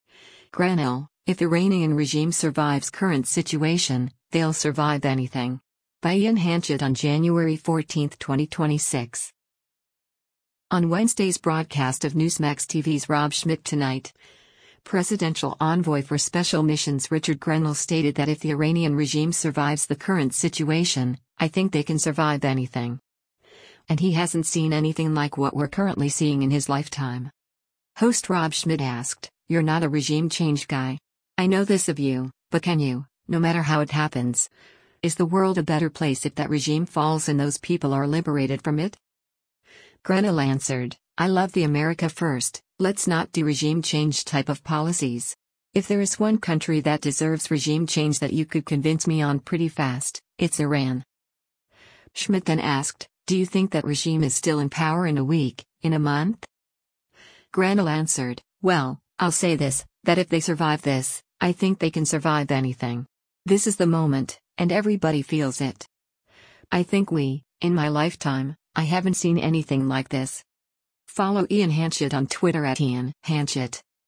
On Wednesday’s broadcast of Newsmax TV’s “Rob Schmitt Tonight,” Presidential Envoy for Special Missions Richard Grenell stated that if the Iranian regime survives the current situation, “I think they can survive anything.” And he hasn’t seen anything like what we’re currently seeing in his lifetime.
Host Rob Schmitt asked, “You’re not a regime change guy. I know this of you, but can you — no matter how it happens, is the world a better place if that regime falls and those people are liberated from it?”